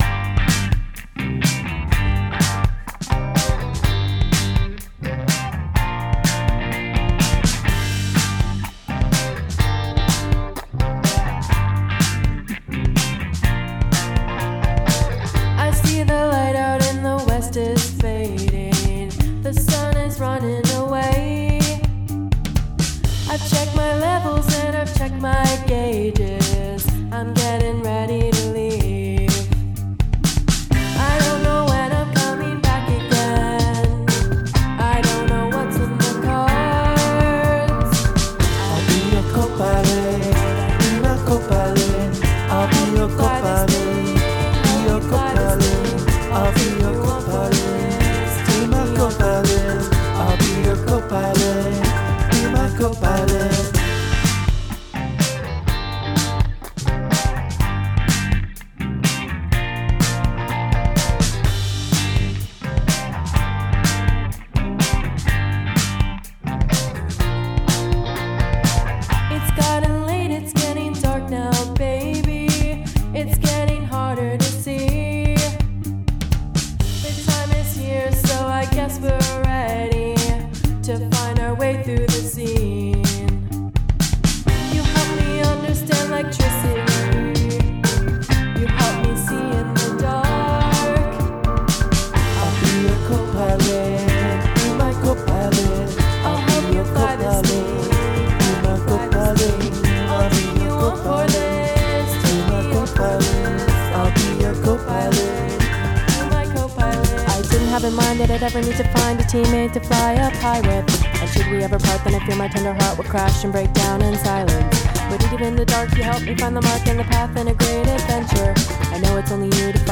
Guest Lead Vocals
Mellow new wave.